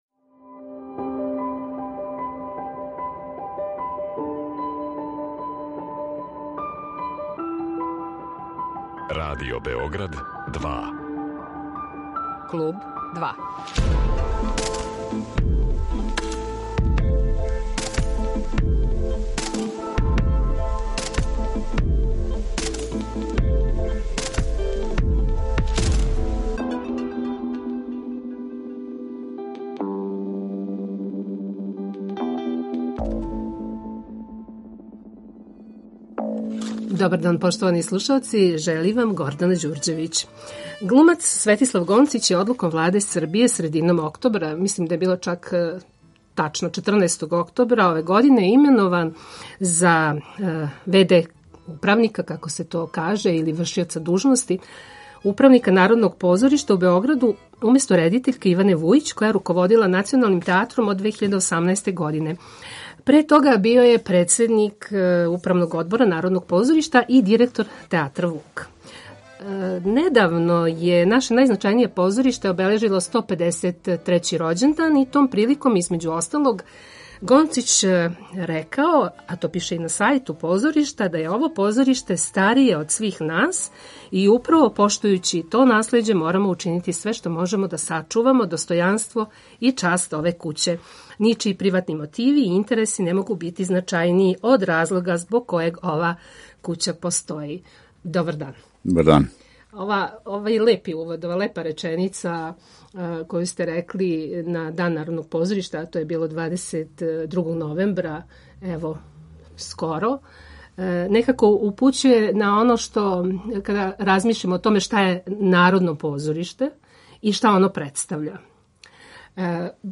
Гост Kлуба 2 је глумац и в.д. управника Народног позоришта у Београду Светислав Гонцић